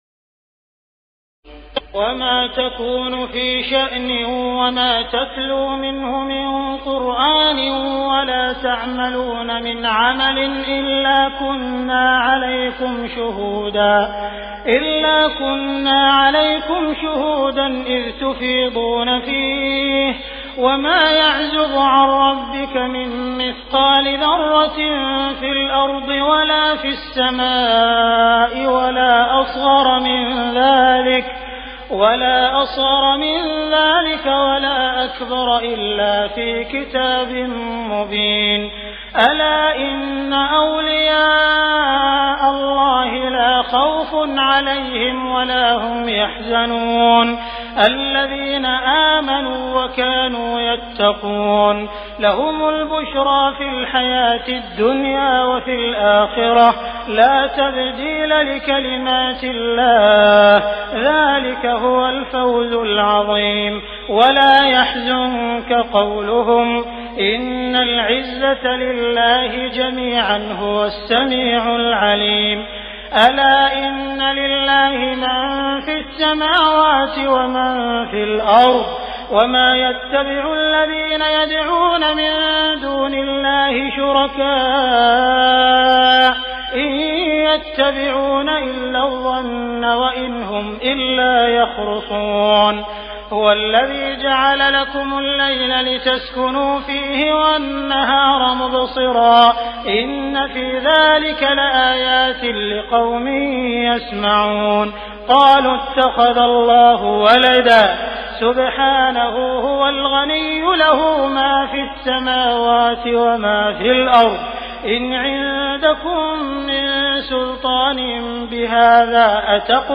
تراويح الليلة الحادية عشر رمضان 1418هـ من سورتي يونس (61-109) و هود (1-49) Taraweeh 11 st night Ramadan 1418H from Surah Yunus and Hud > تراويح الحرم المكي عام 1418 🕋 > التراويح - تلاوات الحرمين